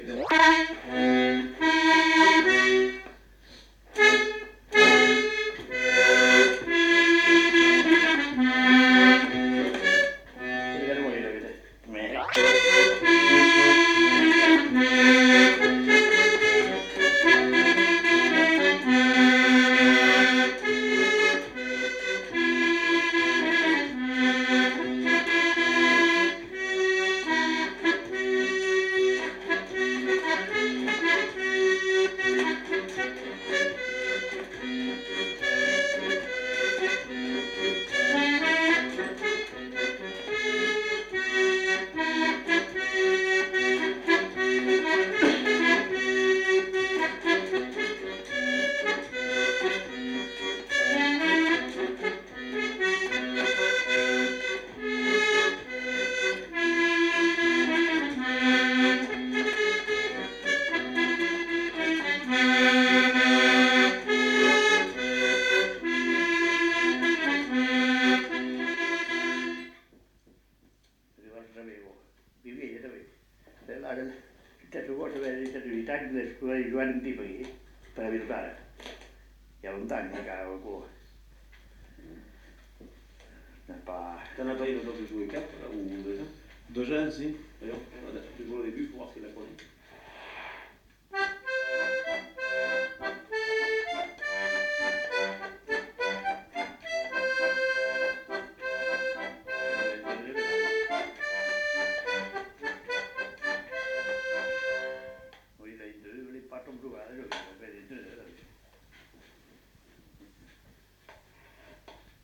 Aire culturelle : Petites-Landes
Lieu : Lencouacq
Genre : morceau instrumental
Instrument de musique : accordéon diatonique
Danse : valse